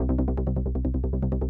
COOL BRASS (308 Kb) NEW